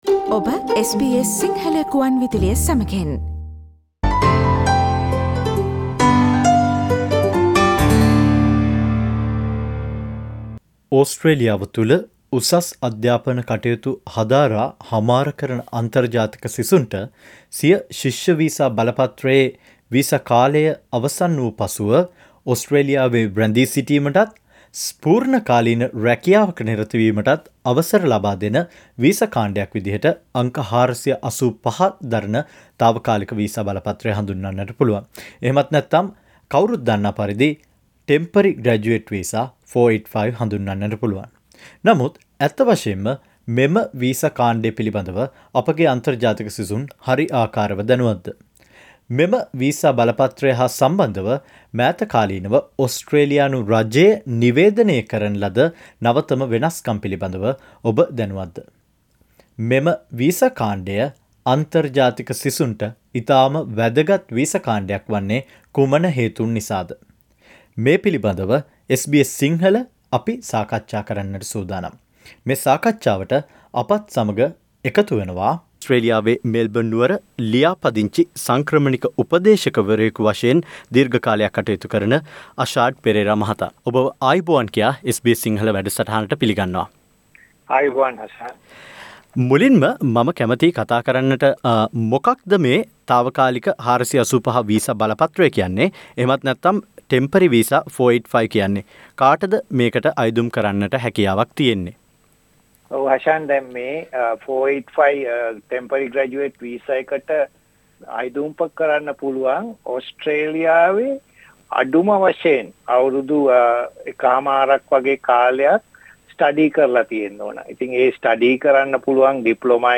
Click on the speaker icon on the above photo to listen to the discussion on important information related to the temporary Graduate 485.